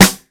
Boom-Bap Snare 85.wav